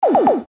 • Качество: 256, Stereo
Короткий звук вустрела из бластера